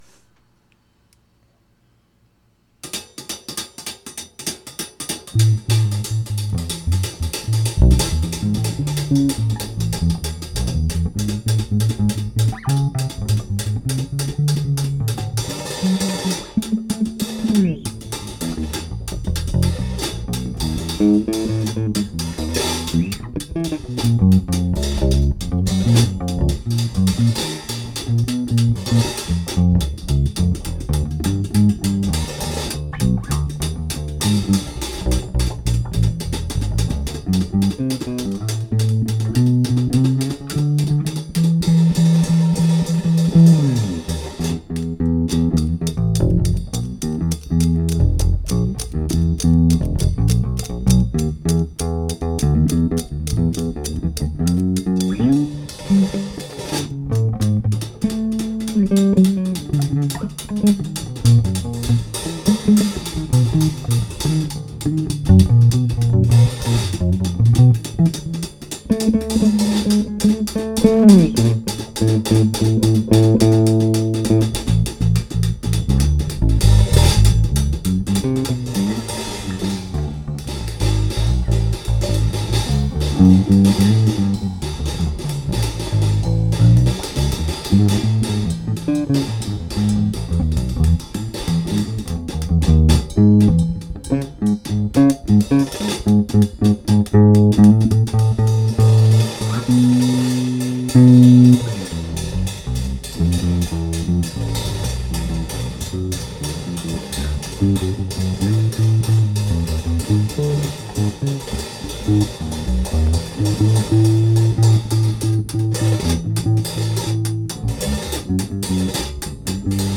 Jazz Improv...experimental.. etc....practice sessions...
bass, guitar..
percussion